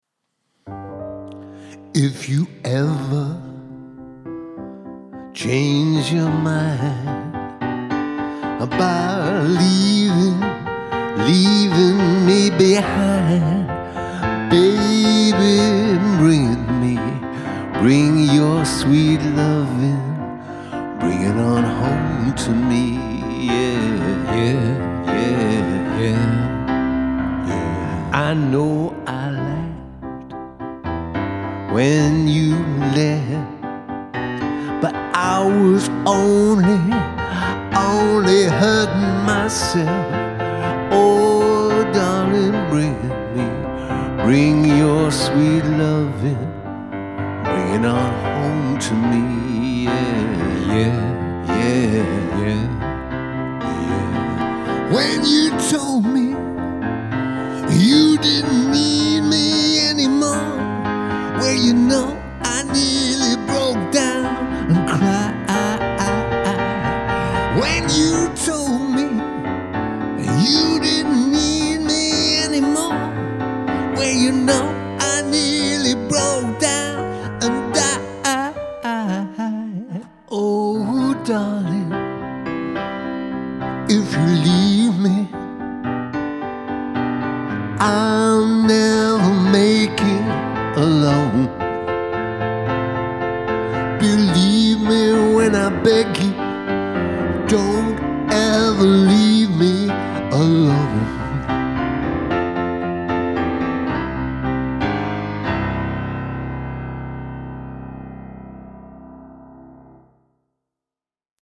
so seamless that it is much more than a mash up.